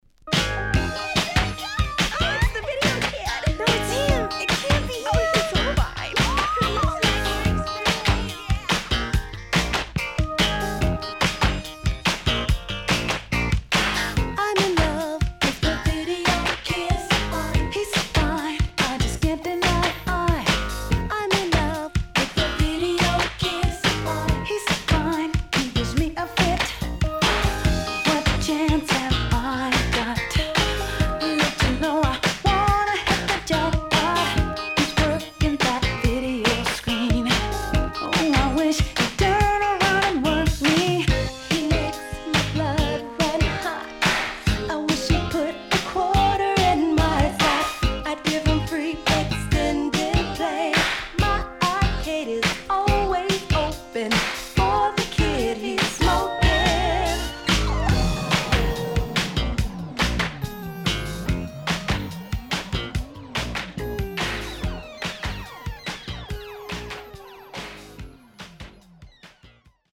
SIDE A:出だし少しノイズ入りますが良好です。